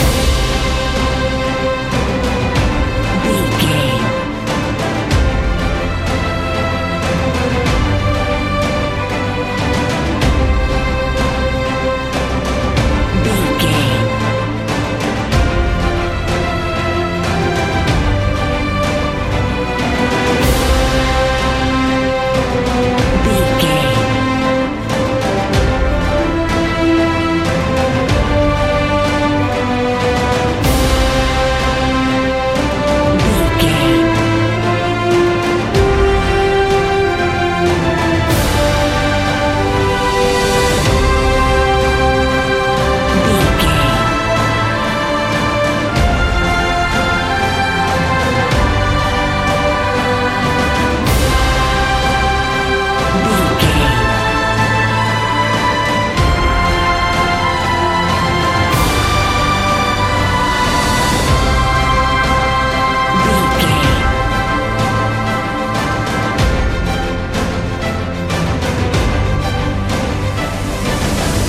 Epic / Action
Uplifting
Ionian/Major
energetic
powerful
brass
drums
strings